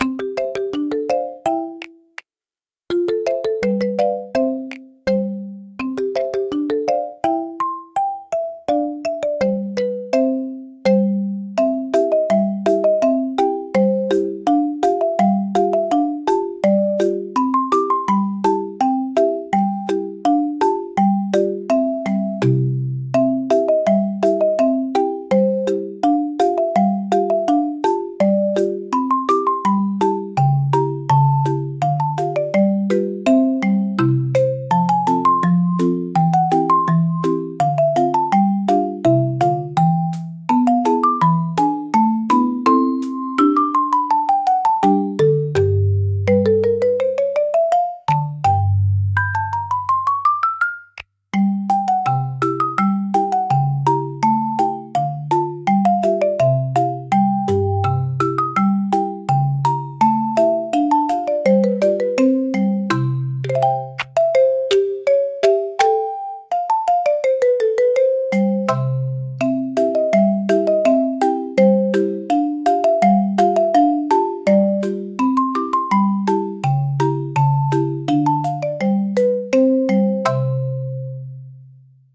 シンプルなメロディラインが心地よいリズムを生み、穏やかながらも前向きなムードを演出します。
ゆったりとした軽快なピアノソロが日常のさりげないシーンを優しく彩るジャンルです。
ピアノの柔らかなタッチが集中をサポートし、疲れを癒す効果を発揮します。